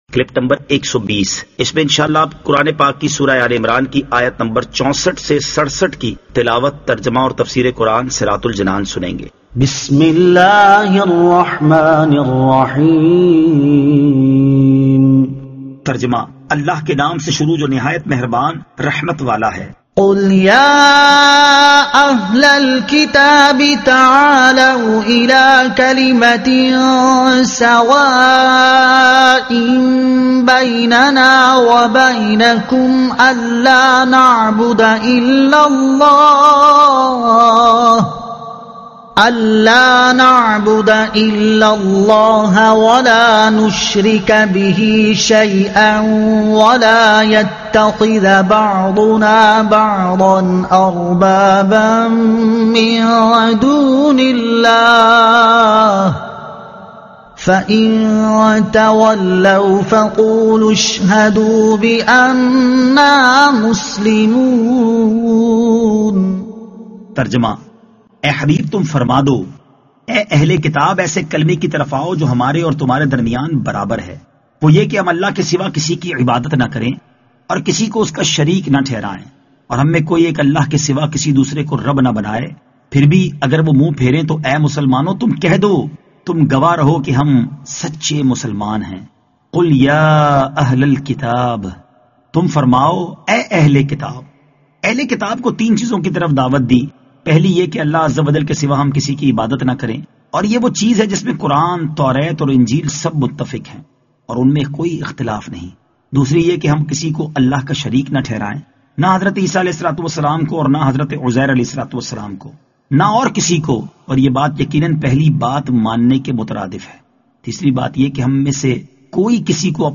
Surah Aal-e-Imran Ayat 64 To 67 Tilawat , Tarjuma , Tafseer